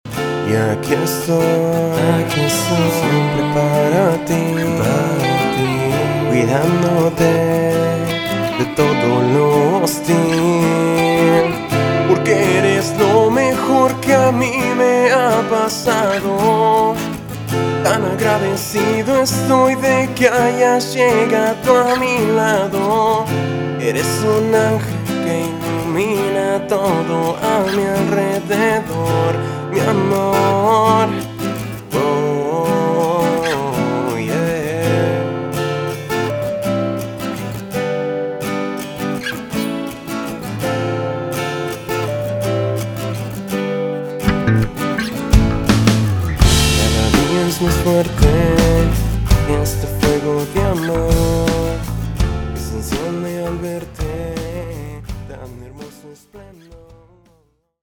Género: Rock / Pop.